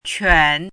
怎么读
quǎn